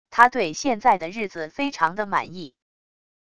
他对现在的日子非常的满意wav音频生成系统WAV Audio Player